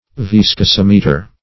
Viscosimeter \Vis`co*sim"e*ter\, n. [Viscosity + -meter.]
viscosimeter.mp3